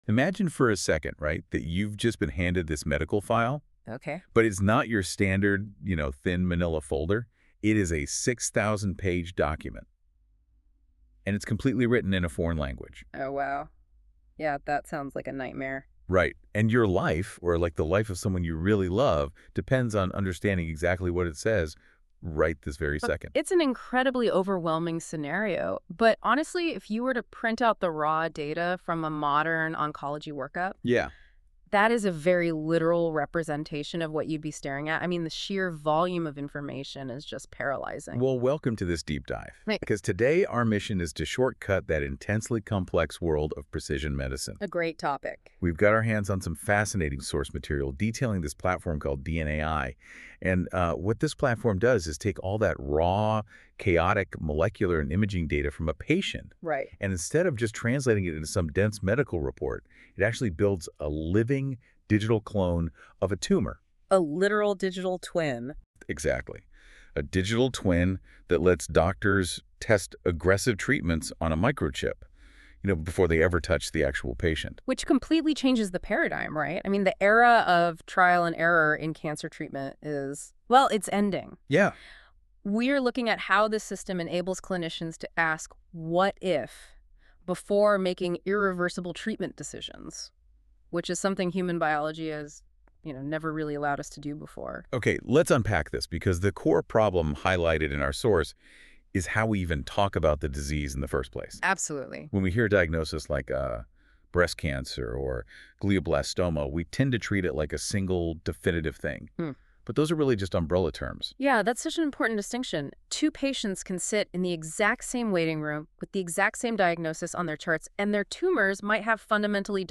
AI-generated podcast — high-level overview of our approach